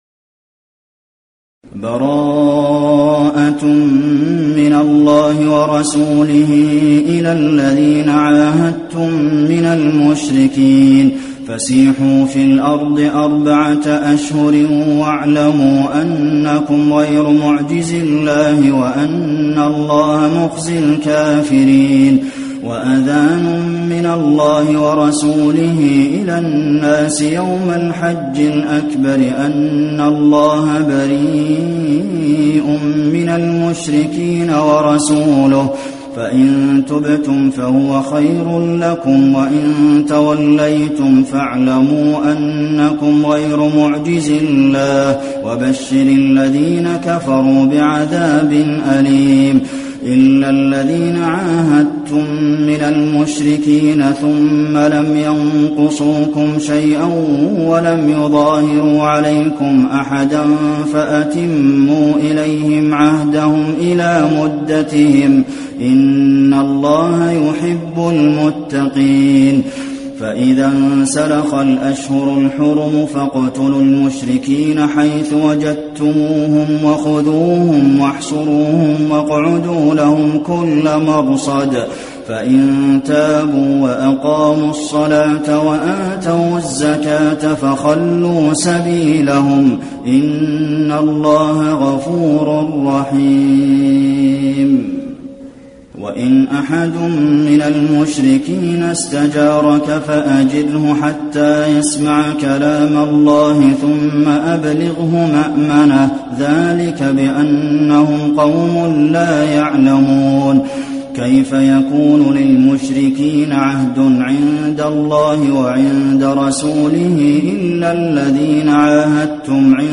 المكان: المسجد النبوي التوبة